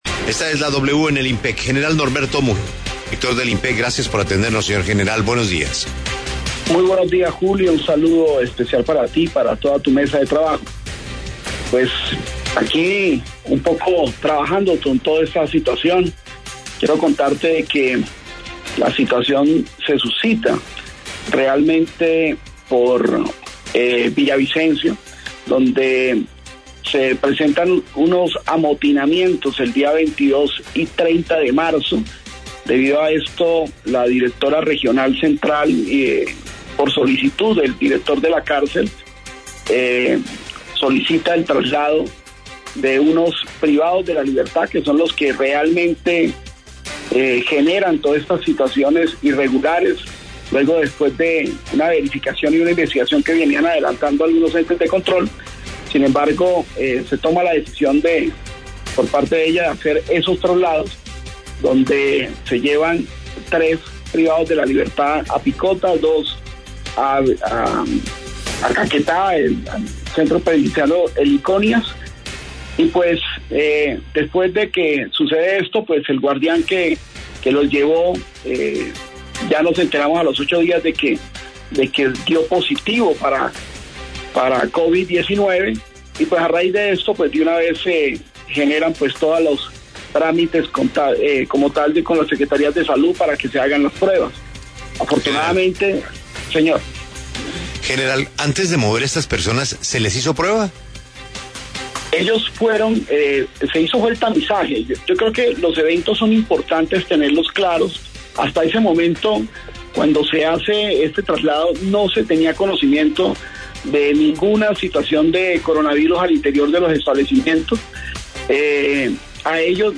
:: Audio :: Declaraciones del Director del INPEC sobre los traslados de internos entre cárceles
declaraciones_general_wradio_programa1_20_abril_01.mp3